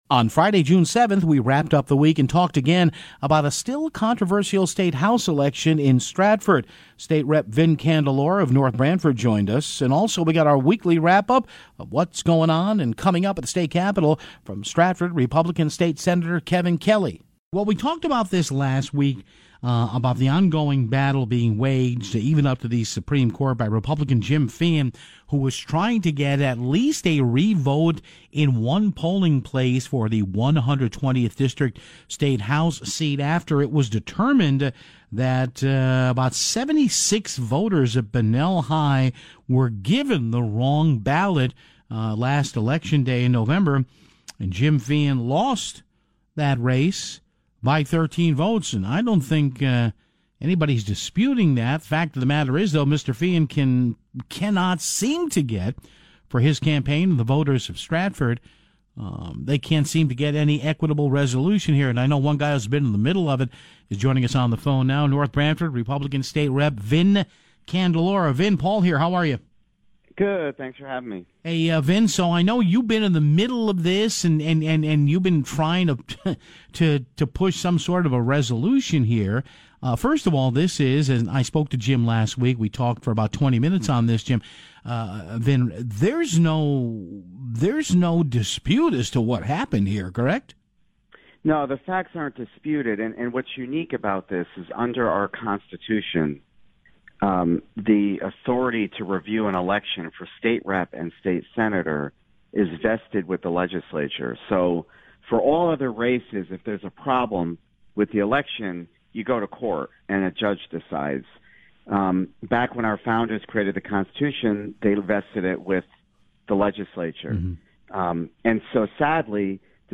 Then, State Senator Kevin Kelly joins the program for his weekly talk.